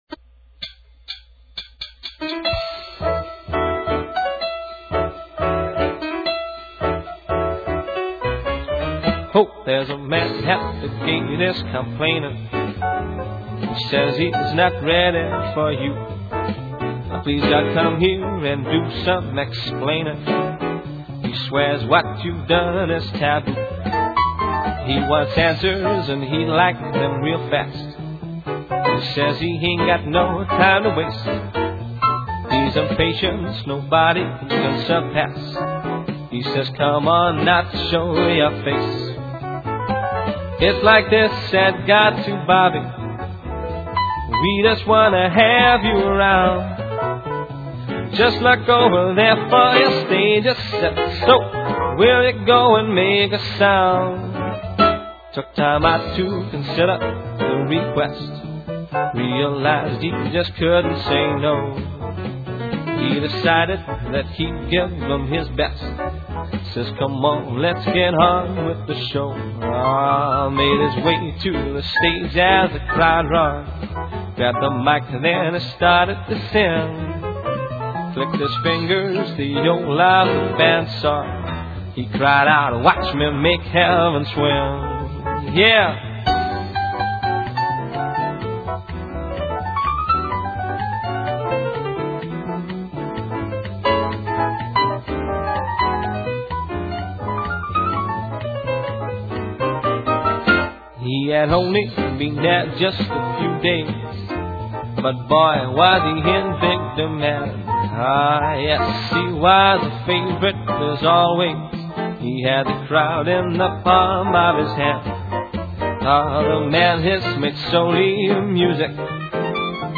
which is so similar to his father's delivery.